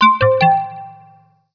threetone-alert.wav